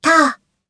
Kara-Vox_Attack3_jp.wav